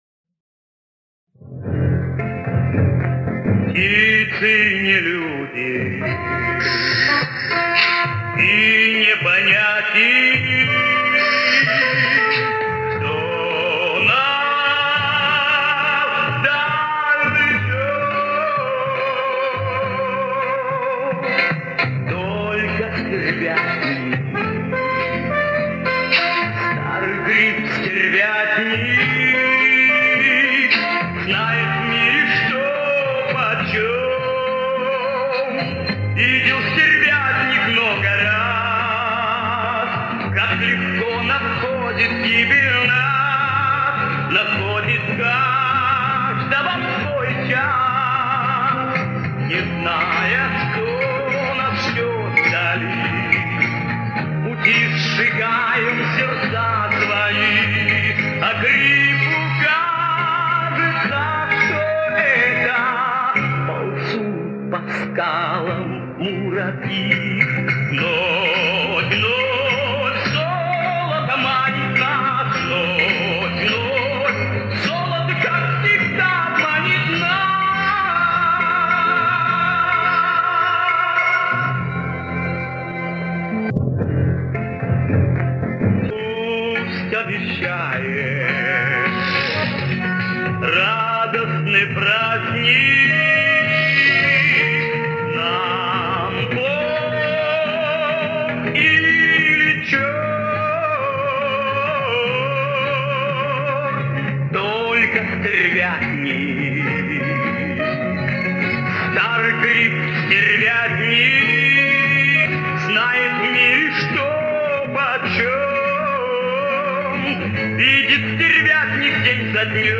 Далеко искать, это- песня в чистом виде :)